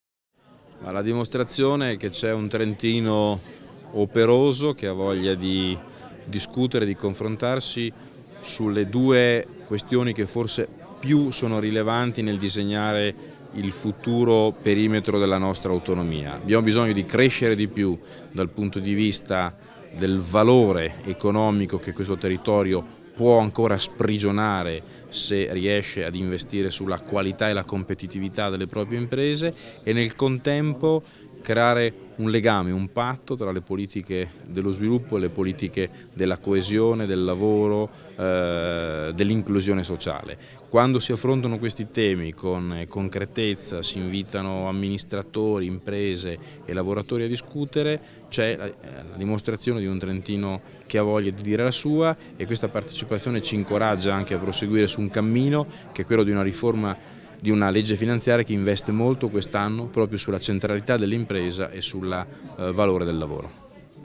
Sala stracolma all'incontro con la Comunità Alta Valsugana